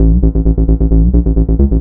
BL 132-BPM A.wav